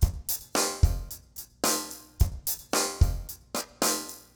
RemixedDrums_110BPM_24.wav